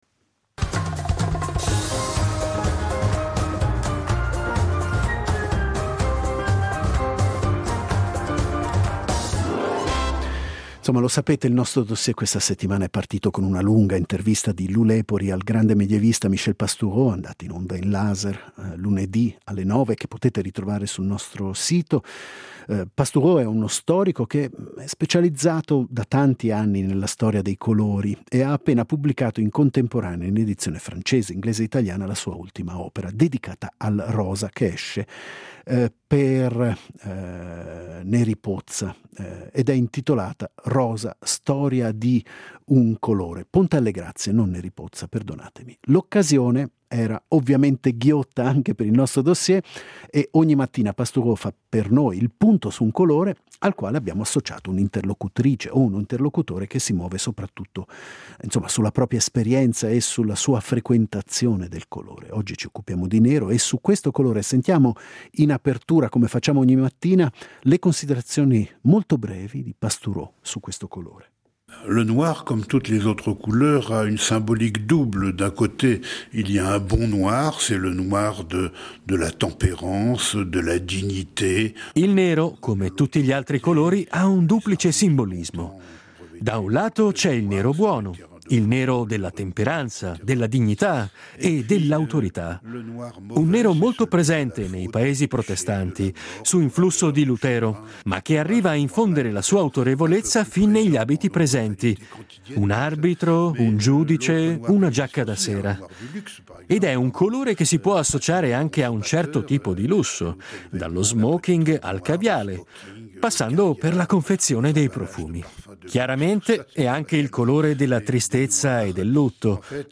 Il Dossier questa settimana prende le mosse da una lunga intervista a Michel Pastoureau , autore del saggio “ Rosa. Storia di un colore”.